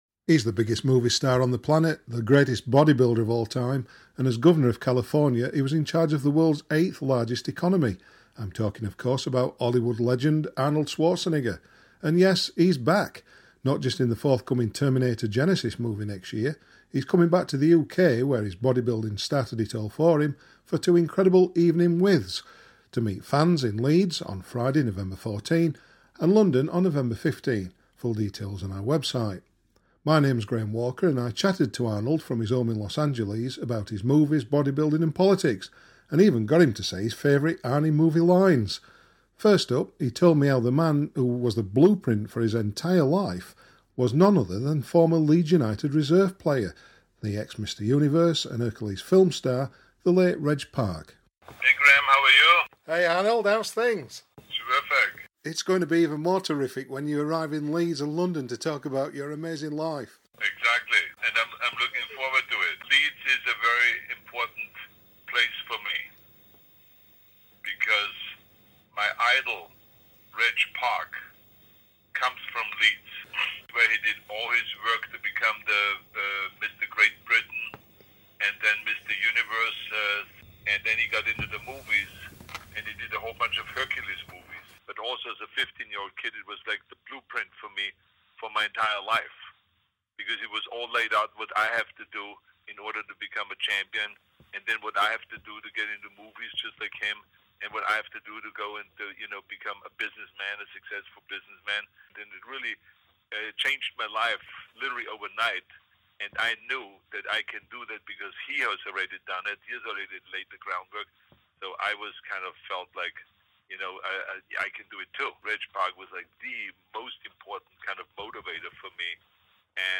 INTERVIEW: Arnold Schwarzenegger